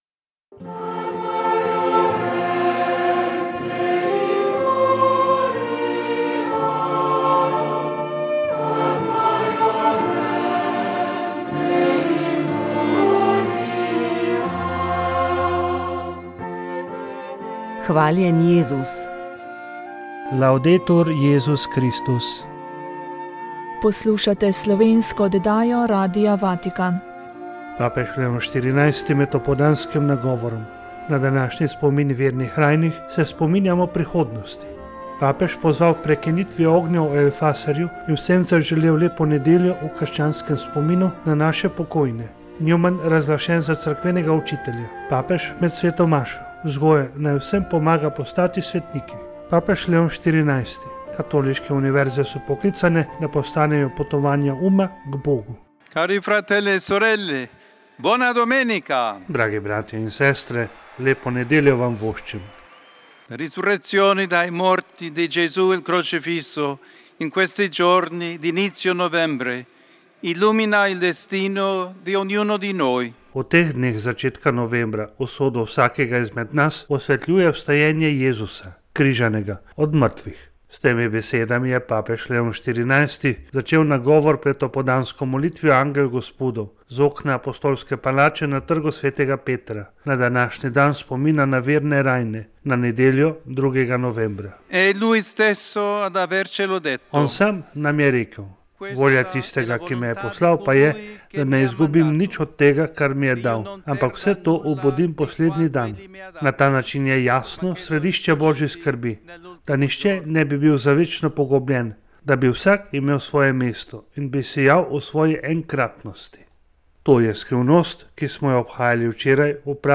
Z začetkom veljavnosti novele zakona o pravilih cestnega prometa lahko vozniki v določenih križiščih zavijejo desno tudi ob rdeči luči na semaforju. V katerih primerih je to mogoče in kaj novega še prinaša novela na področju vožnje z električnimi skiroji, kako bo s kaznimi za prehitro vožnjo...? Gost Svetovalnice je bil državni sekretar Aleš Mihelič.